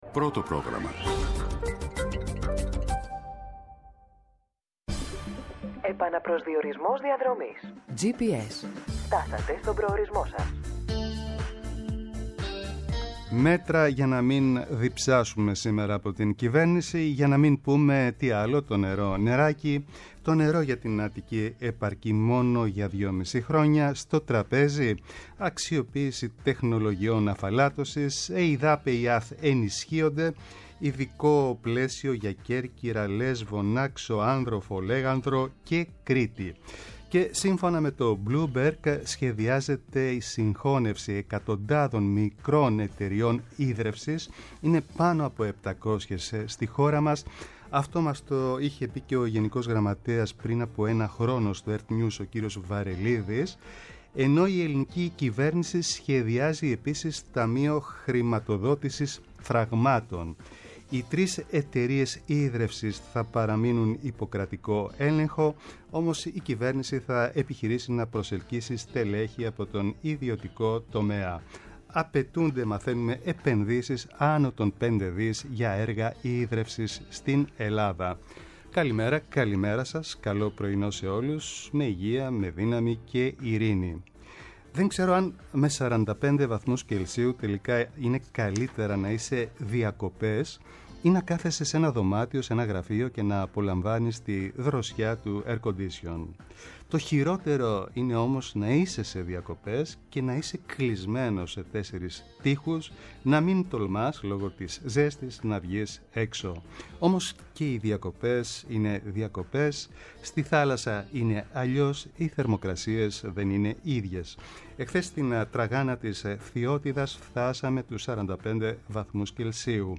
-Ο Γιώργος Γαβριλης, βουλευτής ΣΥΡΙΖΑ, για την πολιτική επικαιρότητα
ΕΡΤNEWS RADIO